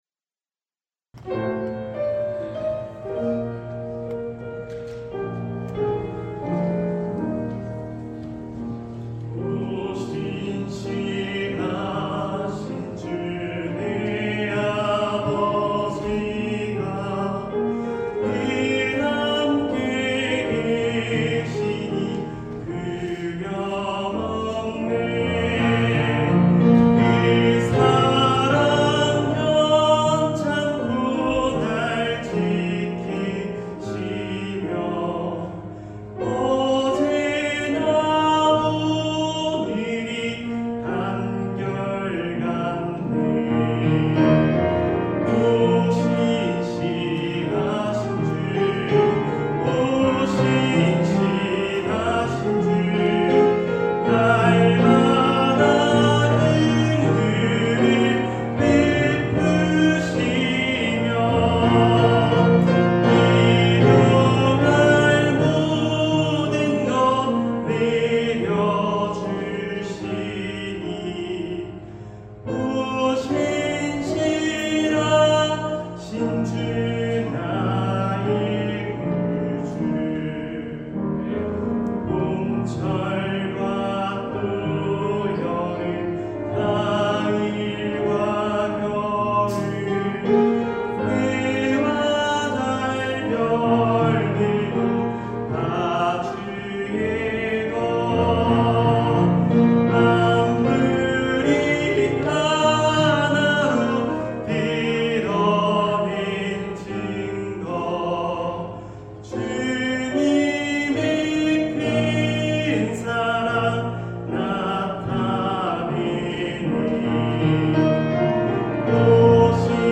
특송